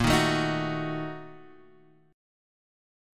A#M7sus4#5 Chord